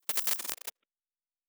pgs/Assets/Audio/Sci-Fi Sounds/Electric/Glitch 3_01.wav at master
Glitch 3_01.wav